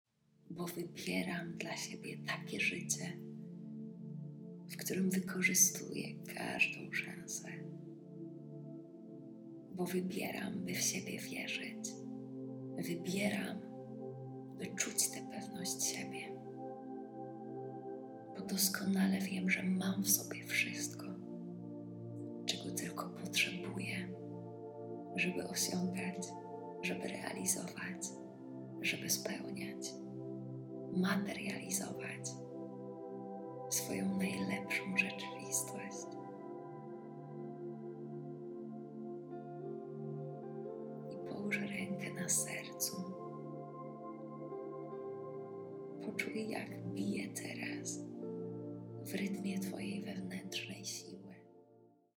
Autohipnoza Programowanie Umysłu na Sukces